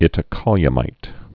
(ĭtə-kŏlyə-mīt)